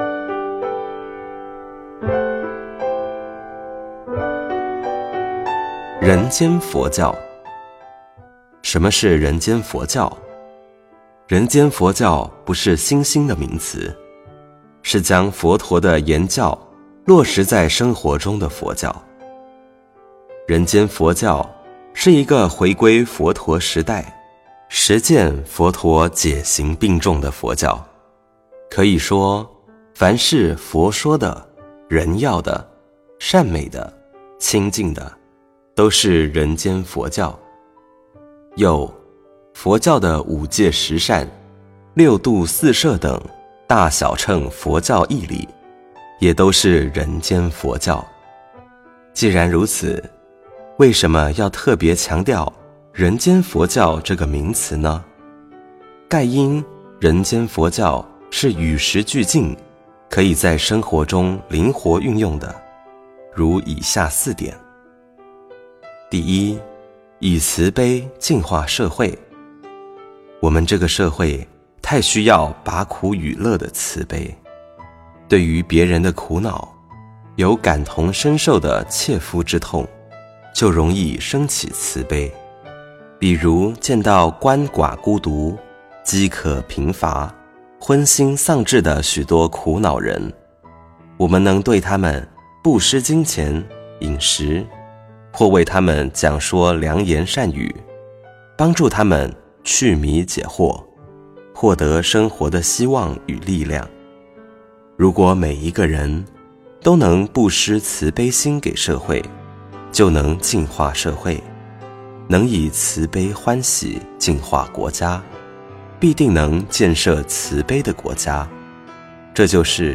佛音 冥想 佛教音乐 返回列表 上一篇： 05.